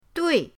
dui4.mp3